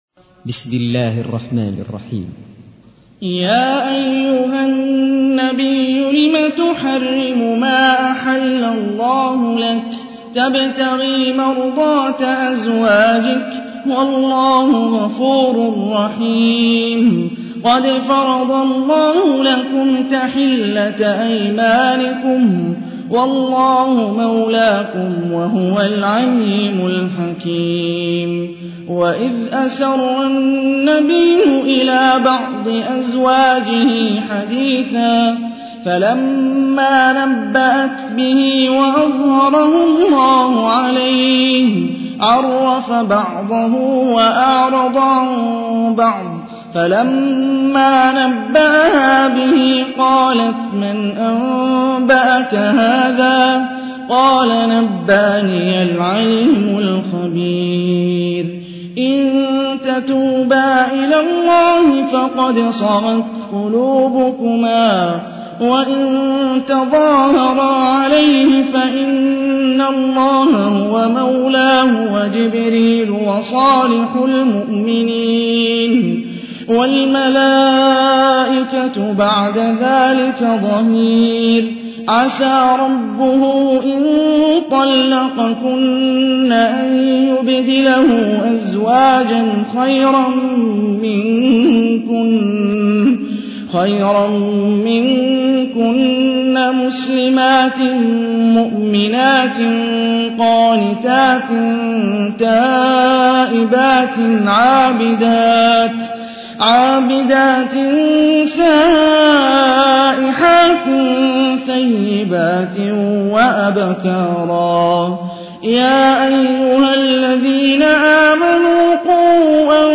تحميل : 66. سورة التحريم / القارئ هاني الرفاعي / القرآن الكريم / موقع يا حسين